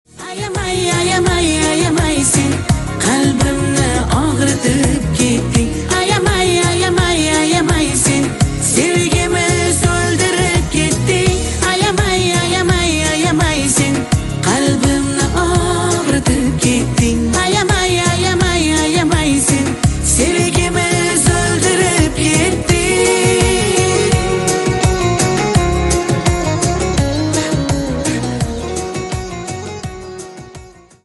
восточные